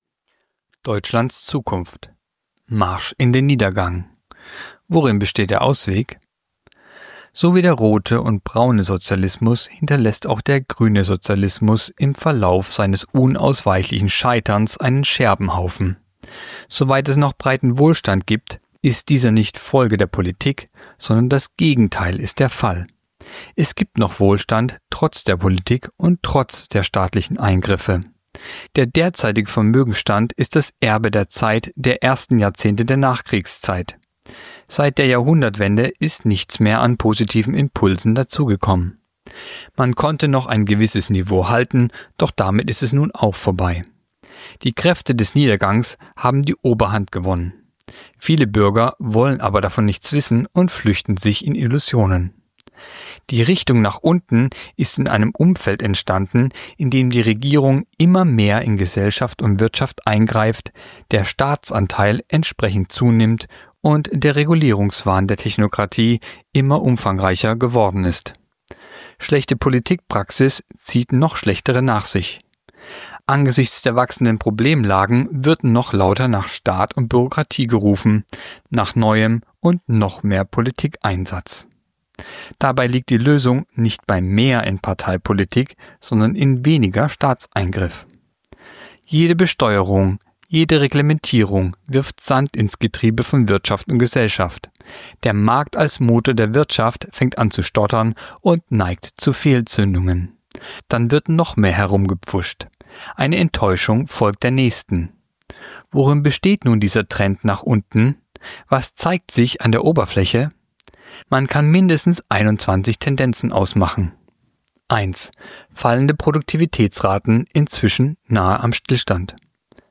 Kolumne der Woche (Radio)Marsch in den Niedergang